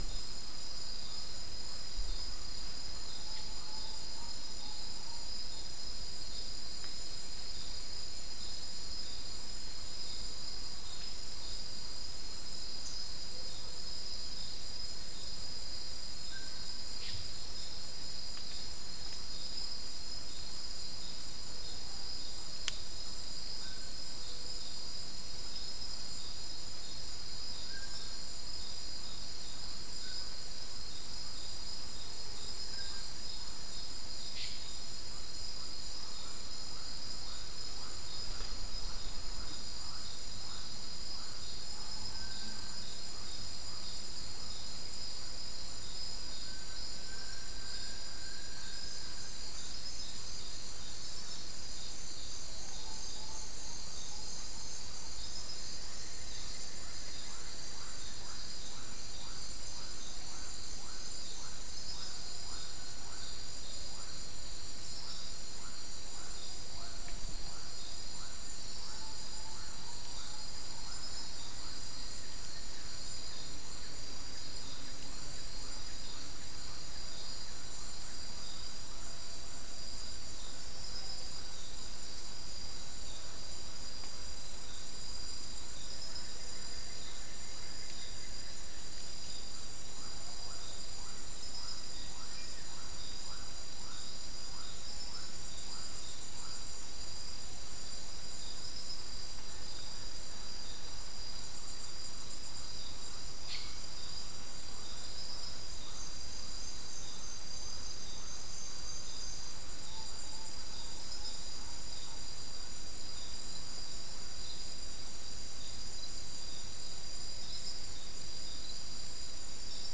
Non-specimen recording: Soundscape Recording Location: South America: Guyana: Turtle Mountain: 4
Recorder: SM3